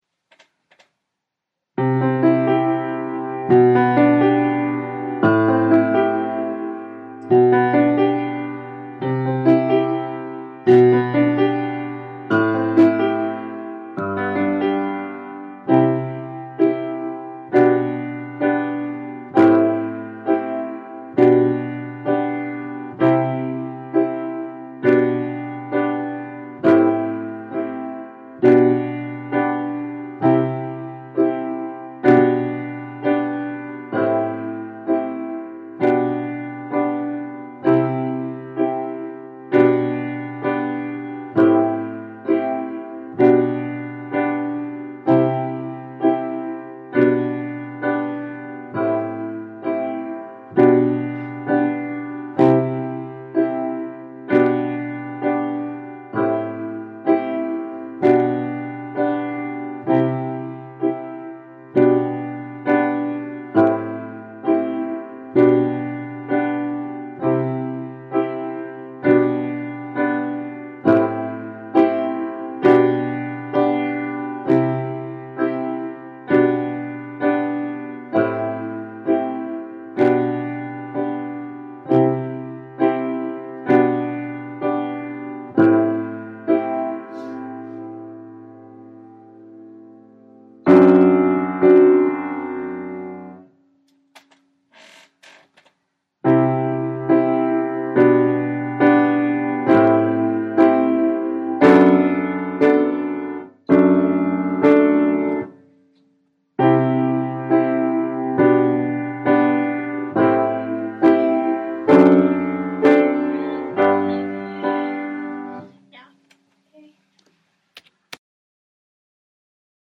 Can be used for Karaoke!